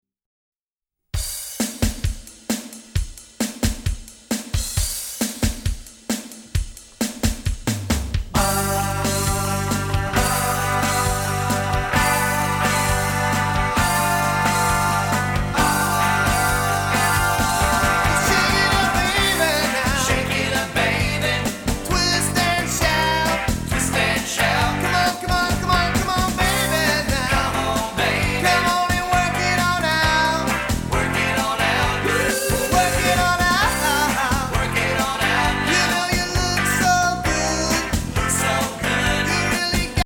exciting and upbeat Rock, Pop and Funk